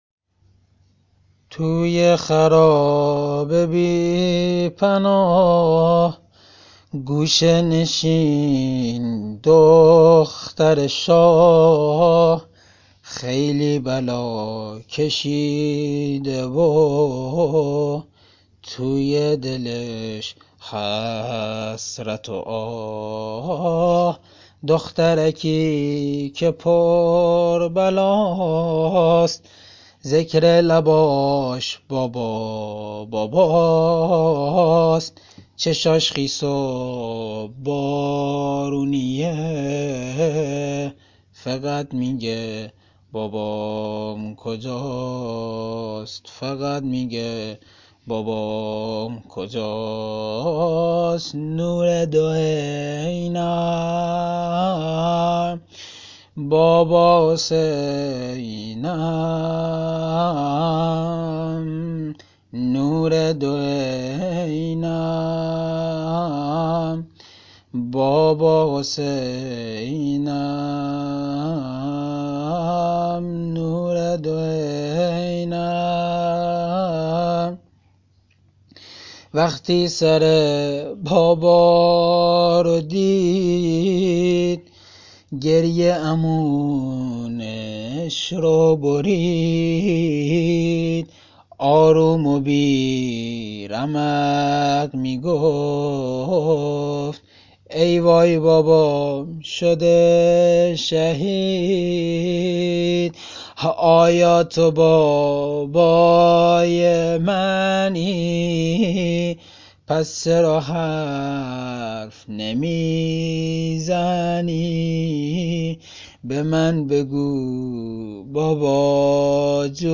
واحد شهادت حضرت رقیه (س) -( توی خرابه بی پناه گوشه نشینْ دخترِ شاه )